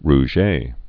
(r-zhā)